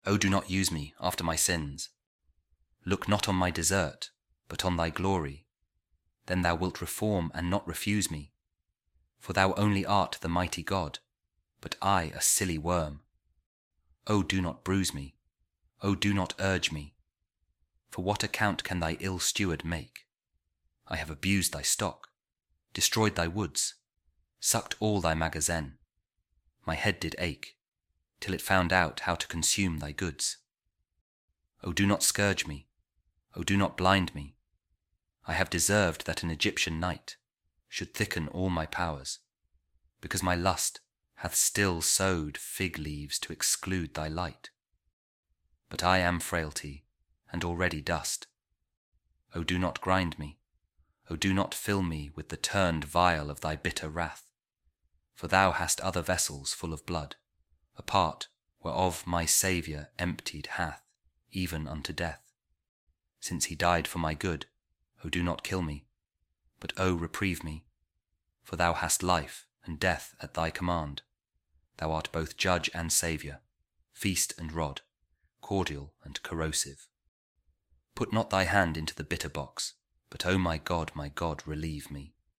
george-herbert-sighs-groans-audio-poem-christian.mp3